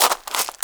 GRAVEL 1.WAV